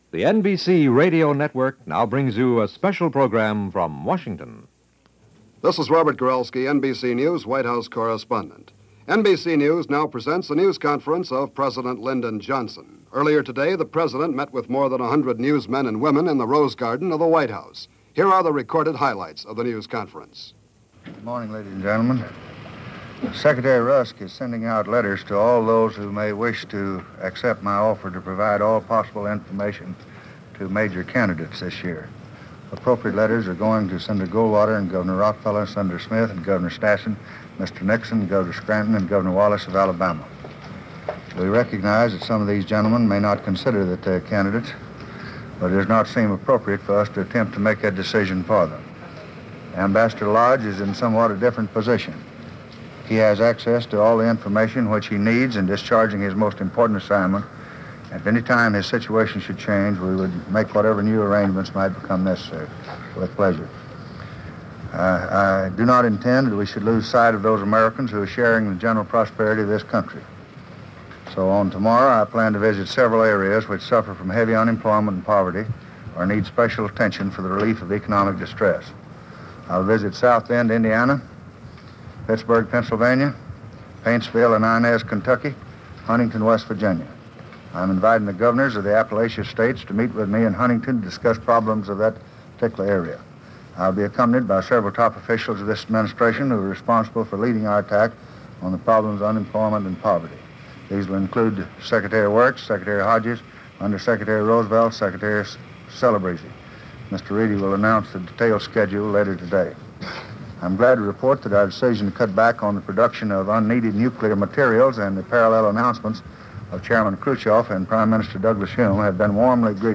April 23, 1964 - An LBJ Press Conference - Past Daily Reference Room: Presidents Being Presidential
April 23, 1964 - An LBJ Press Conference -All Networks.
An LBJ Press Conference: Spring 1964 – No drama, no hysterics.